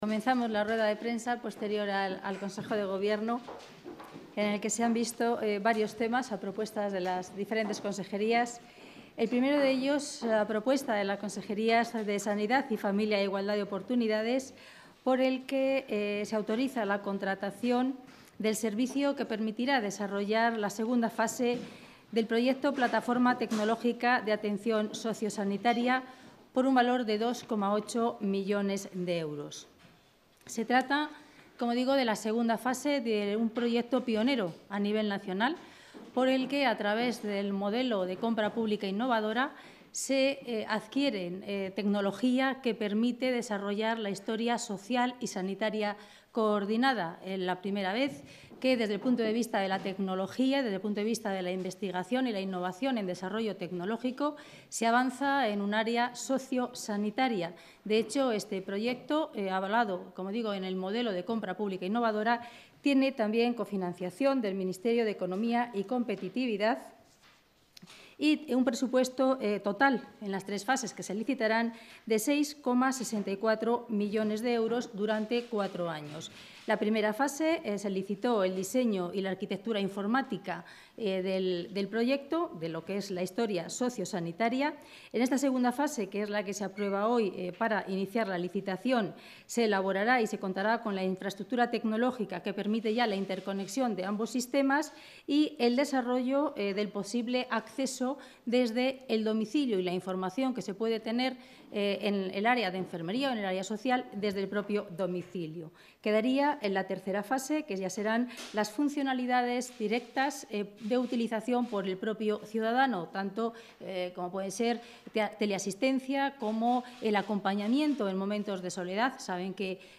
Rueda de prensa tras el Consejo de Gobierno.